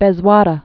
(bĕz-wädə)